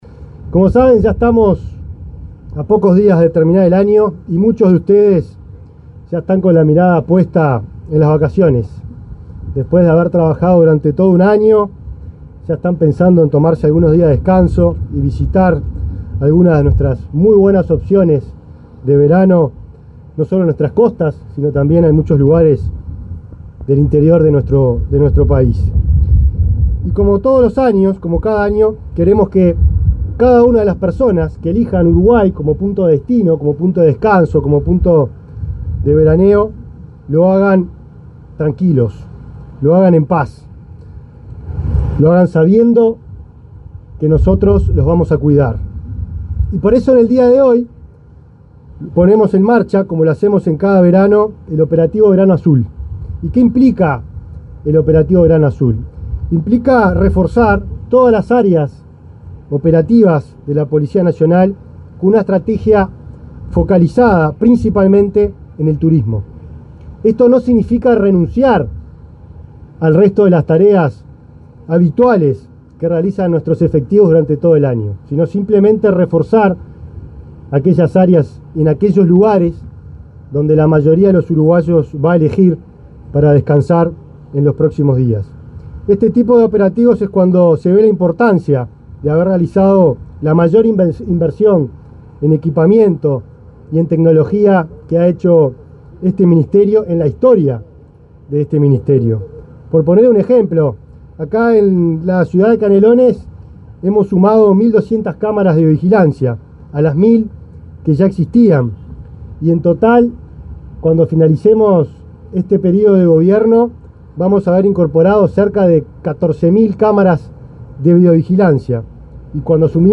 Palabras del ministro del Interior, Nicolás Martinelli
Palabras del ministro del Interior, Nicolás Martinelli 17/12/2024 Compartir Facebook X Copiar enlace WhatsApp LinkedIn El ministro del Interior, Nicolás Martinelli, participó, en el lanzamiento de los operativos de seguridad Verano Azul, realizado este martes 17 en Atlántida, departamento de Canelones.